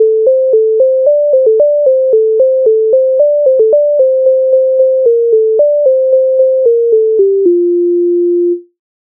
MIDI файл завантажено в тональності F-dur
Попід терном стежечка Українська народна пісня з обробок Леонтовича с,136